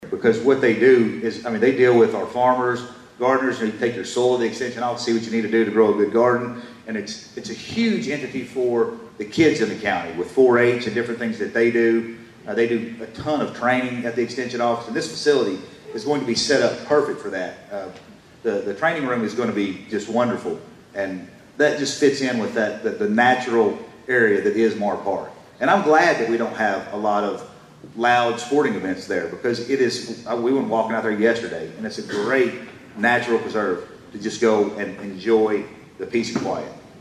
During last week’s State of the Cities and County Address, Madisonville Mayor Kevin Cotton and Hopkins County Judge-Executive Jack Whitfield shared updates on local projects, including the addition of the new extension office at Mahr Park Arboretum.